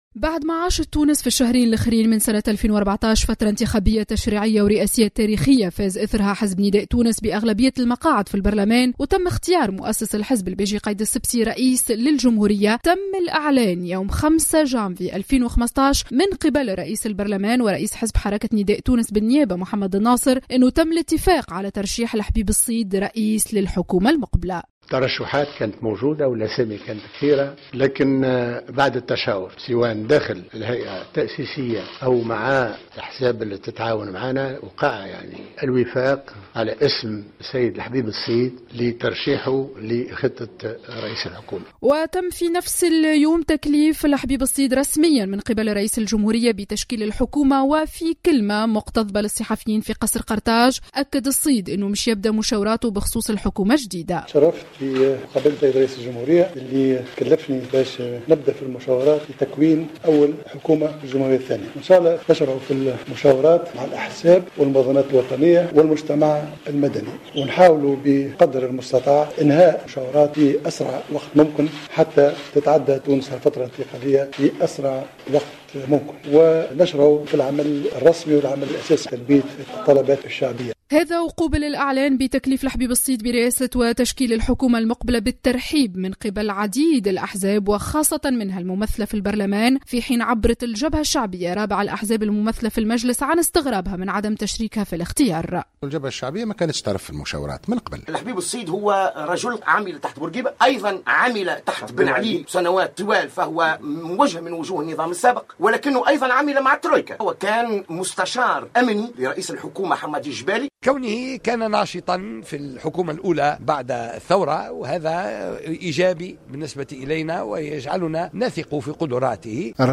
وفيما يلي أكثر تفاصيل في تقرير